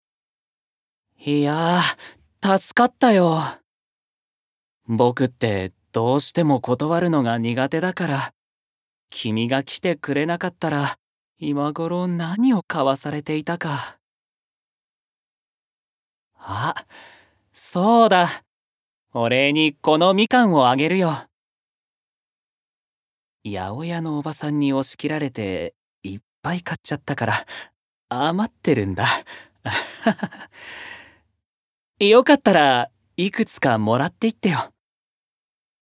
Voice Sample
ボイスサンプル
セリフ４